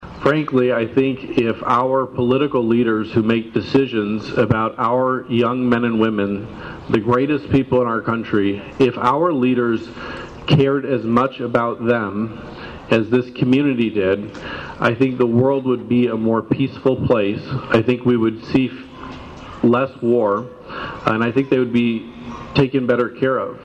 Coldwater’s Memorial Day ceremony was held in the newly renovated Four Corners Park in front of a large gathering on a sun splashed morning.